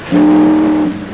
- boatwhistle
tokyowhistle.wav